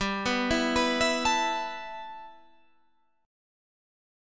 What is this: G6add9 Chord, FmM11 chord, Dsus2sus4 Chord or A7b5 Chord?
G6add9 Chord